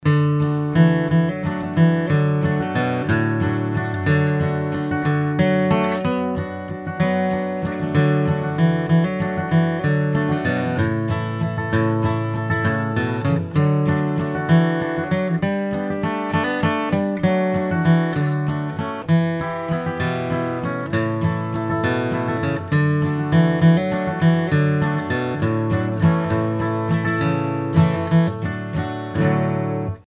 Neulich habe ich etwas herumgedaddelt und dabei ist etwas in dieser Art hängengeblieben, das ist allerdings im 3/4-Takt.
Walzer, Einflechten einer Melodie,
flatpicking_waltz_1.mp3